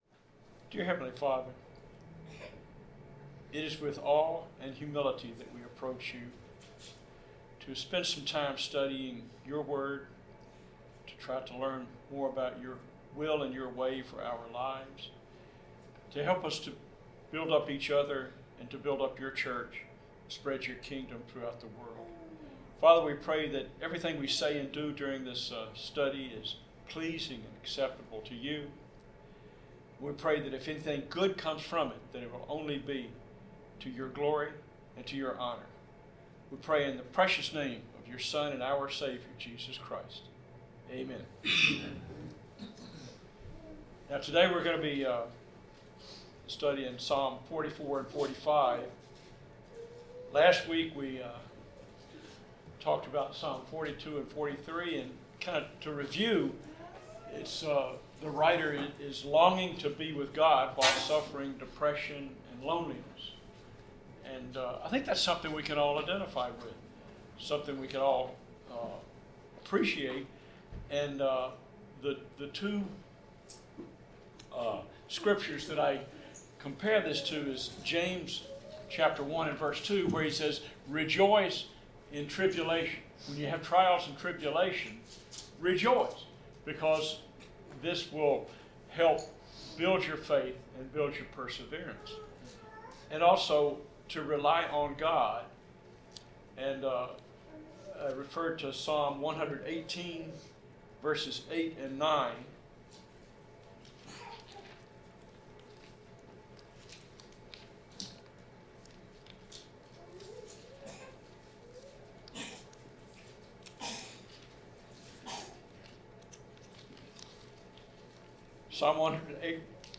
Bible class: Psalms 44-45
Service Type: Bible Class